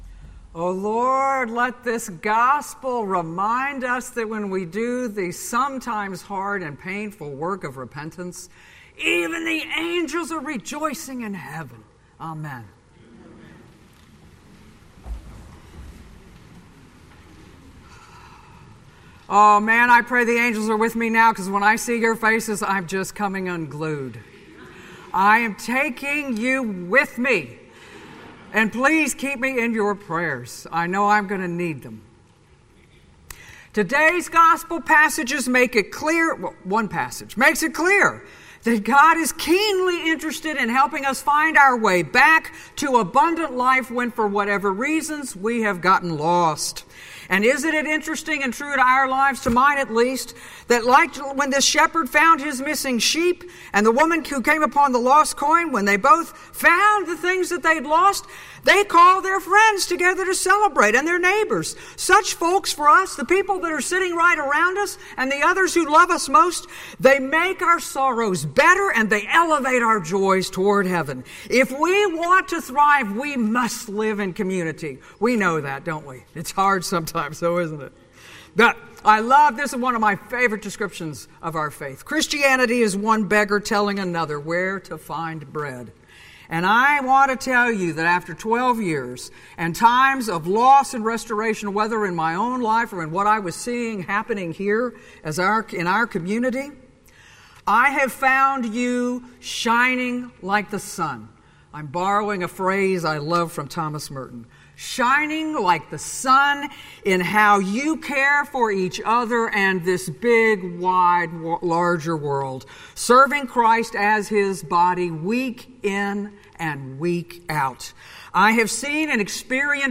Fourteenth Sunday After Pentecost Sermons at Christ Episcopal Church, Rockville, MD
Farewell Sermon